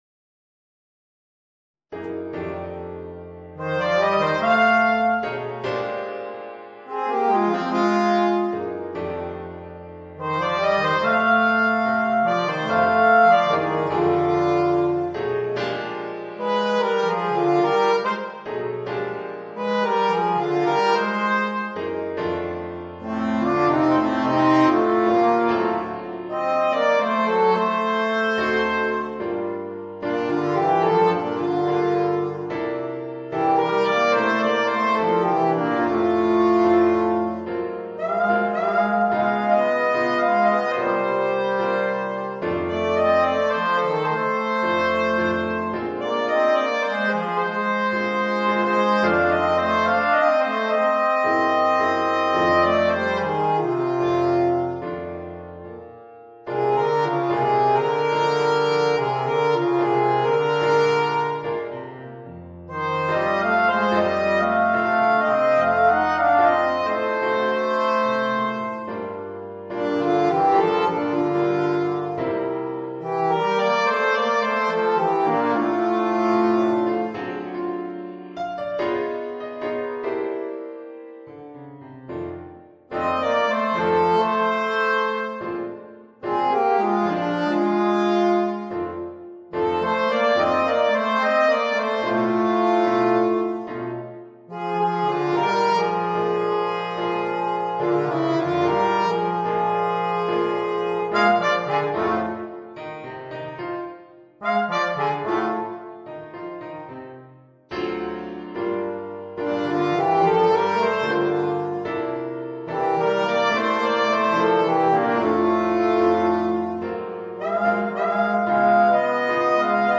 Voicing: Alto Saxophone, Trombone and Piano